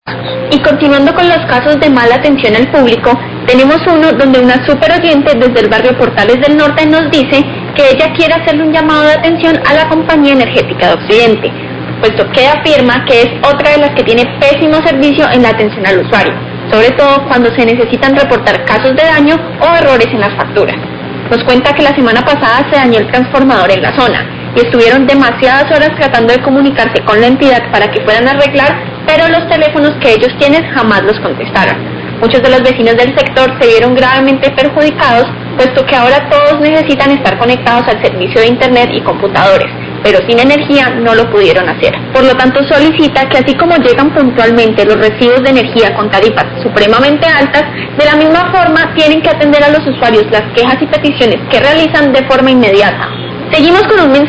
Radio
Oyente del barrio Portales del Norte se queja contra la Compañía Energética por el pésimo servicio de atención al usuario. La semana pasada se dañó un transformador y por horas trataron de comunicarse pero jamás contestaron.